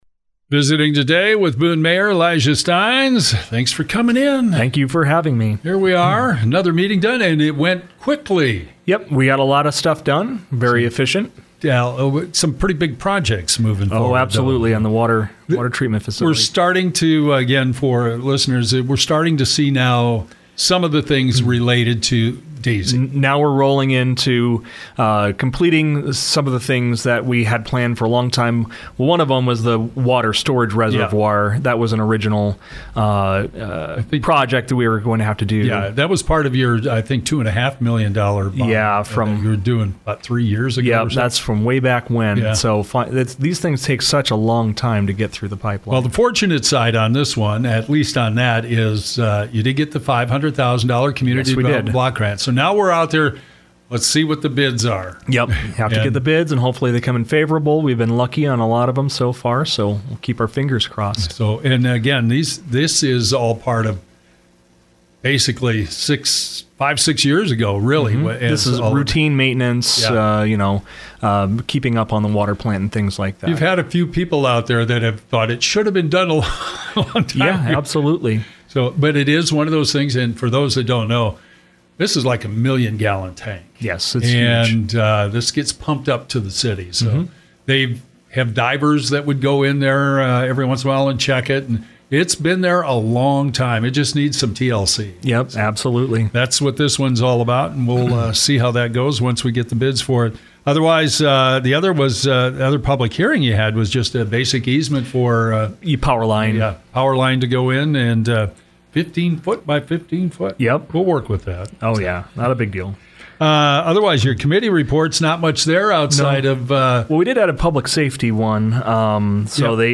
Boone Mayor Elijah Stines talks about the Boone City Council meeting held Monday.